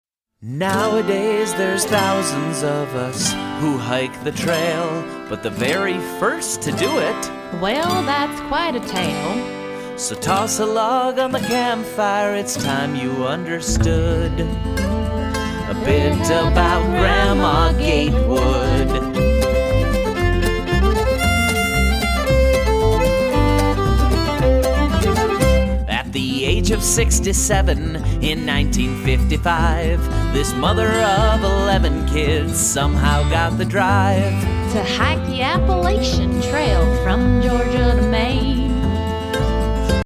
Cheerful songs jubilantly performed